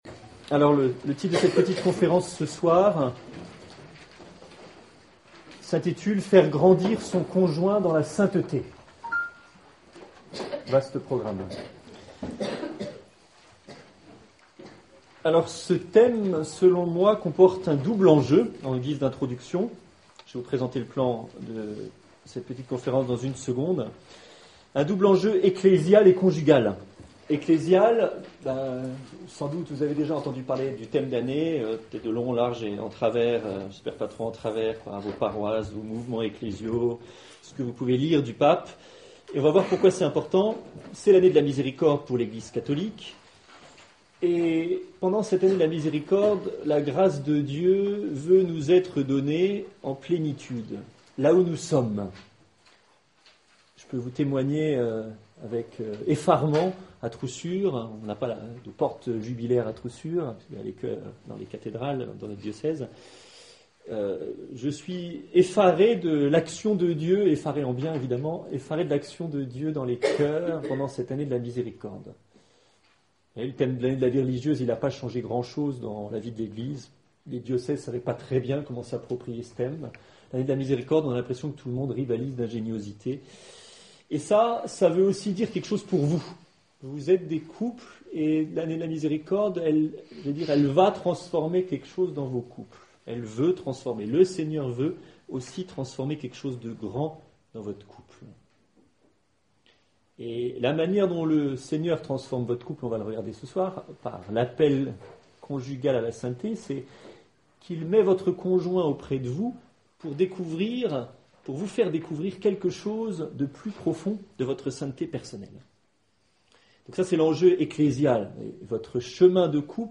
Conférence 07/04/16 : Faire grandir son conjoint dans la sainteté
Conférence-faire-grandir-son-conjoint.mp3